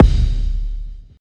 Kicks
stacked_kick.wav